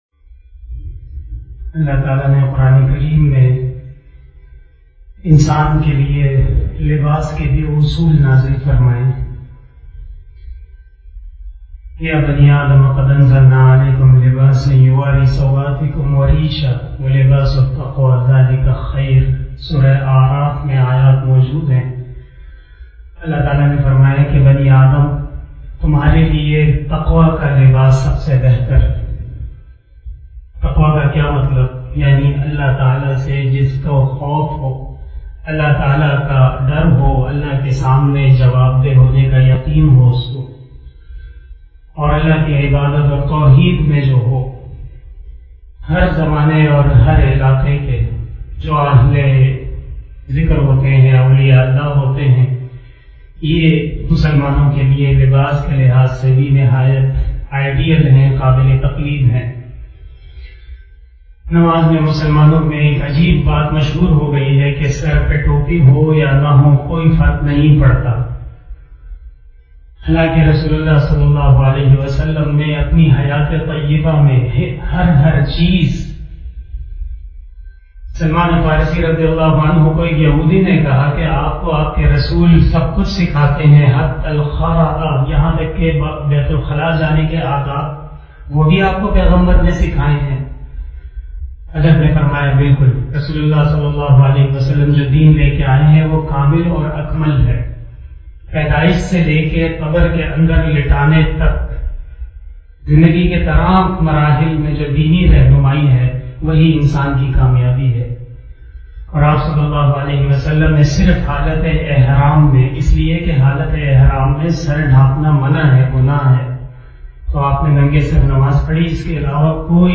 032 After Asar Namaz Bayan 09 July 2021 (29 Zulqadah 1442HJ) Friday